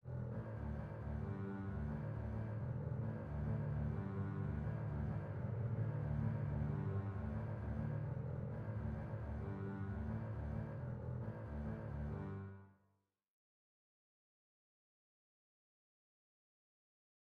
Il s’agit d’une musique tourmentée, mouvante, tout en contrastes qui allie la noirceur à une lumière souvent blafarde et qui correspond à une époque où le monde s’apprête à replonger dans le désastre, à l’orée des années 30.
Tout va commencer dans les ténèbres de l’orchestre, par une sorte de magma sonore fait de notes tenues par les violoncelles et contrebasses 2 agrémentées d’un motif tournoyant en sextolets de doubles croches joué sur les cordes à vide par les contrebasses 1. Les notes importantes sont ici le mi, le la et le ré.